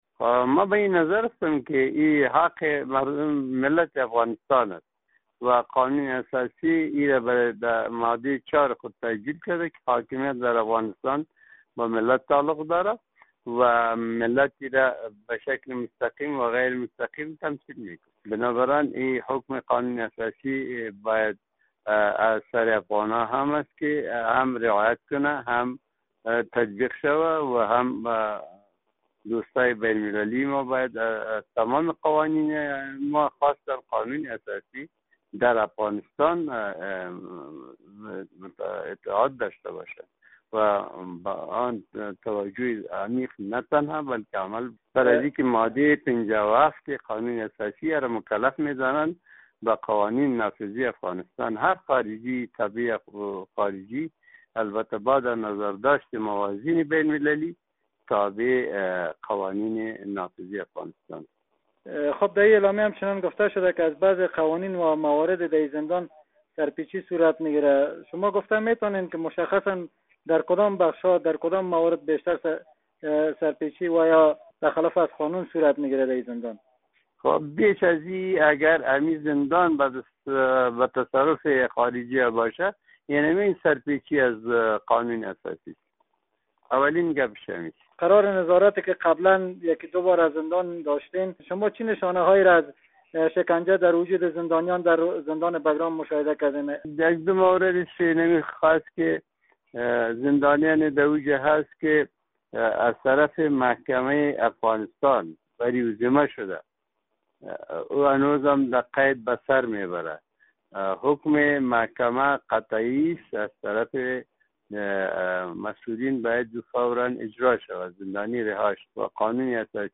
مصاحبه در مورد واگذاری مسوولیت زندان بگرام به حکومت افغانستان